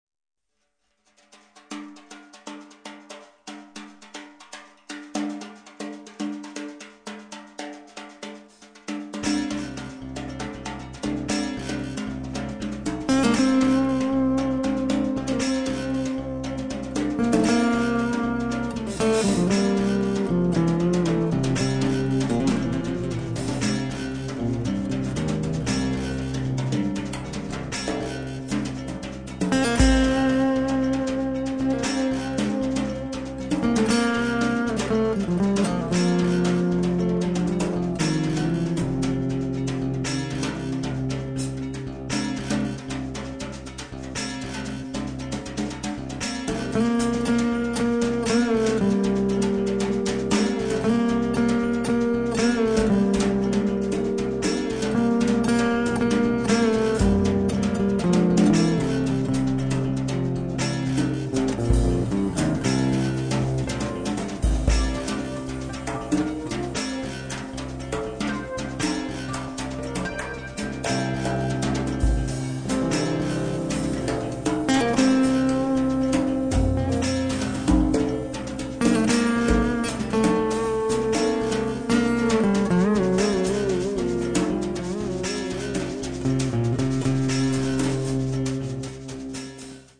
chitarra
batteria e percussioni